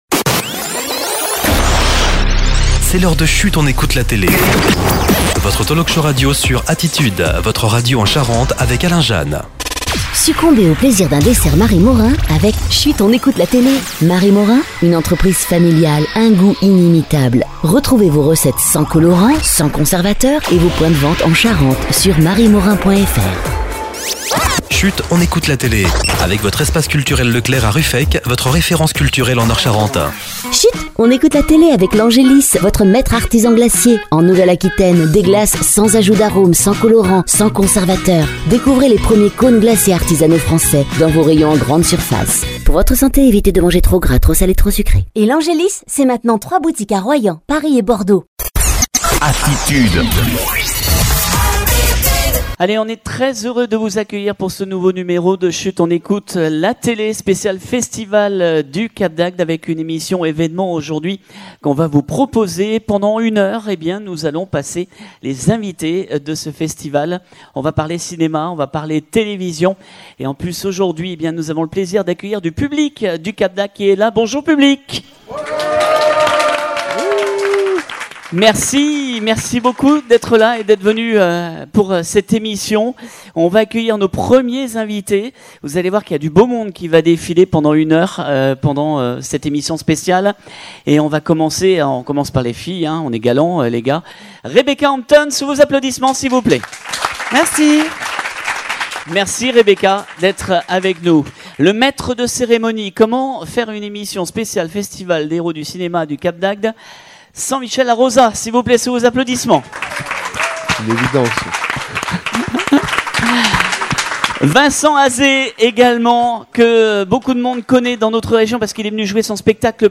Chut on écoute la télé de ce lundi 27 Juin en live du Cap D'Agde avec plus de 15 invités en podcast et vidéo - Chut... on écoute la télé
C’était une émission Chut on écoute la télé un peu folle que nous vous avons proposé ce lundi 27 juin 2022 en live depuis le festival “les hérault du cinéma” au Cap d’Agde avec de nombreux invités